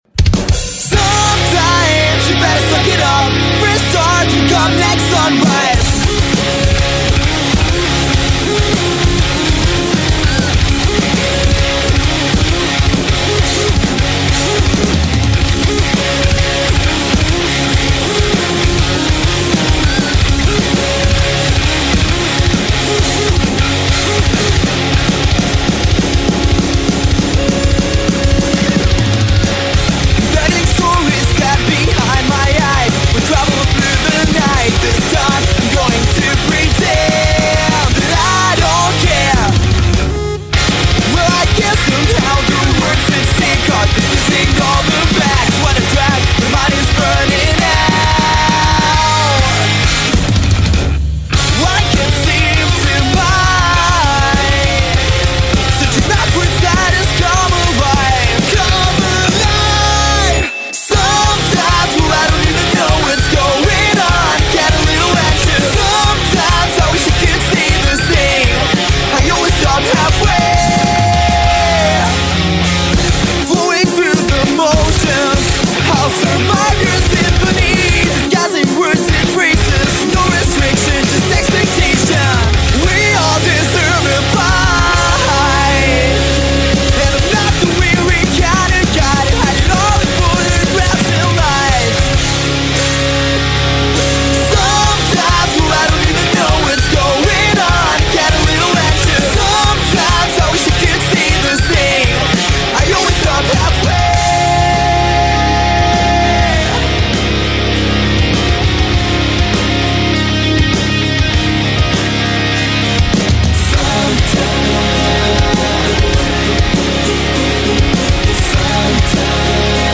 pop punk band